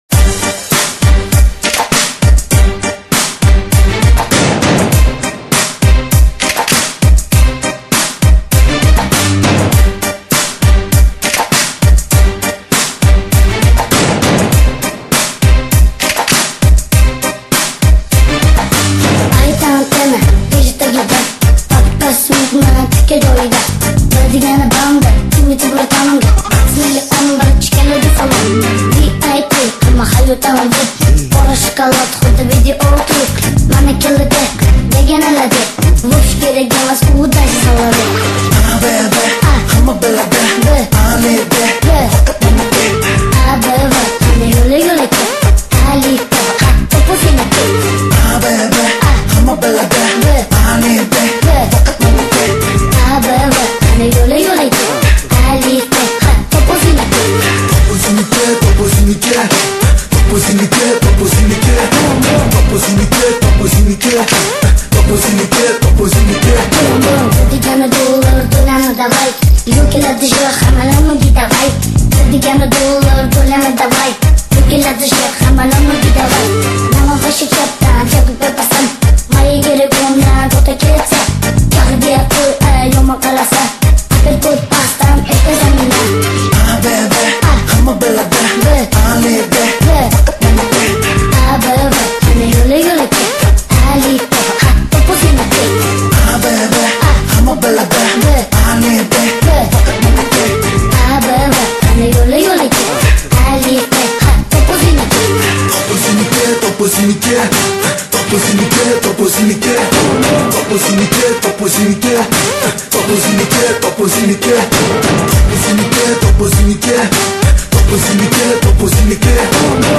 强力DJ慢摇舞曲 引爆迪厅音乐嗨潮
为低音质MP3